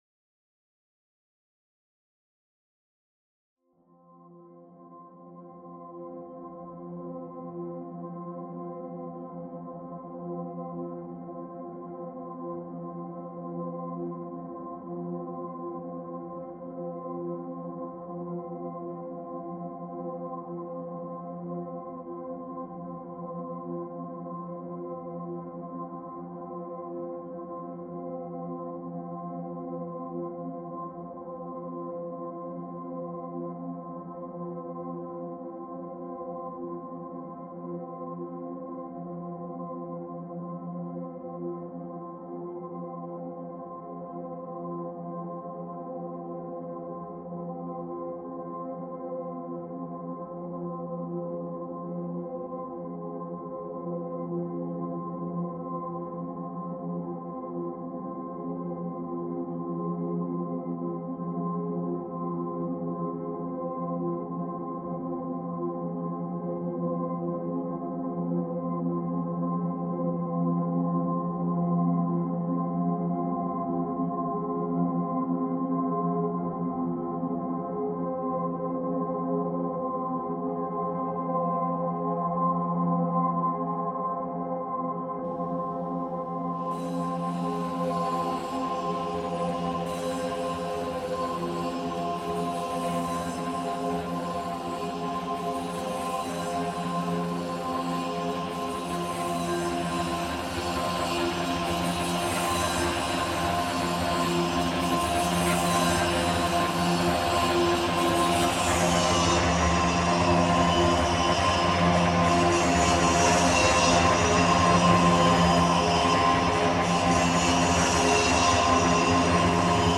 Construction noise in New York reimagined